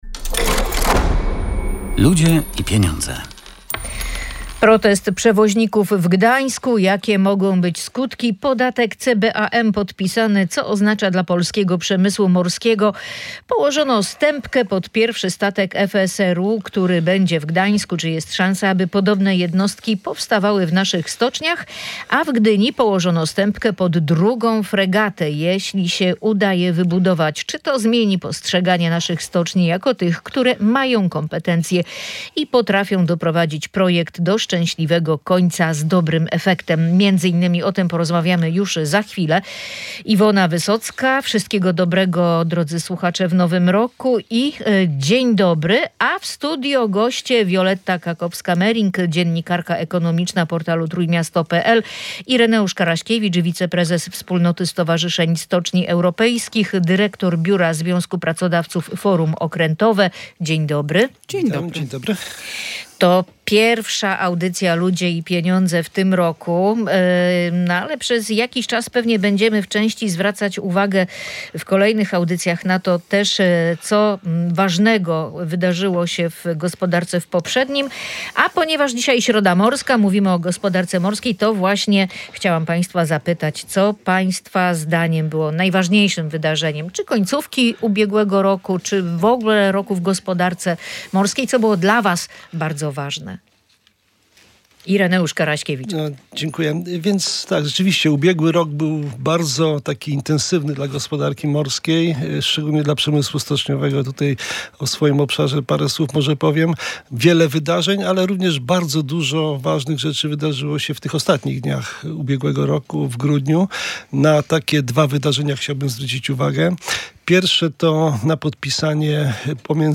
Eksperci komentują protesty przewoźników